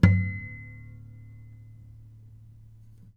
ostinato_and_interrupt / samples / strings_harmonics / 1_harmonic / harmonic-12.wav
harmonic-12.wav